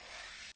cf_short_slide.ogg